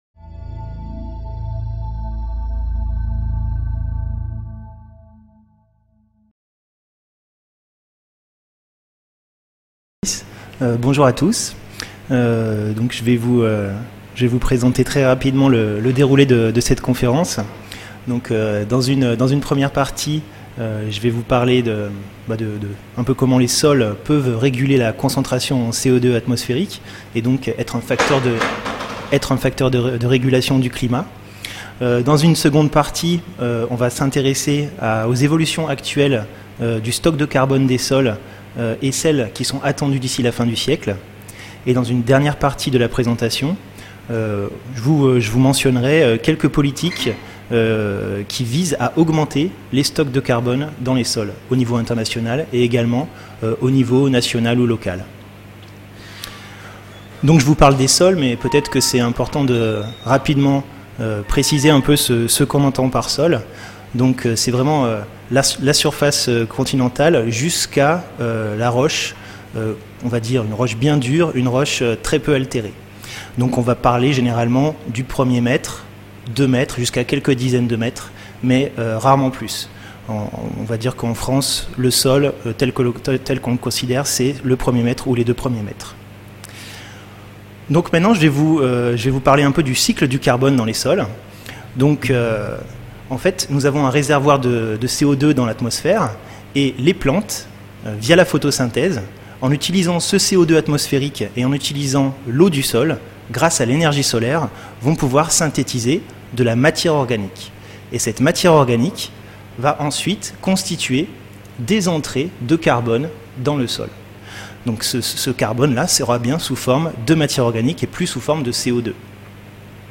Cette conférence présentera comment les sols influencent le climat en tant que réservoir dynamique de carbone. Après avoir rappelé l’origine, la nature du carbone des sols et son importance pour la qualité des sols, le conférencier montrera que l’évolution des flux de carbone entre les sols et l’atmosphère est une composante majeure de celle de la concentration en CO2 atmosphérique.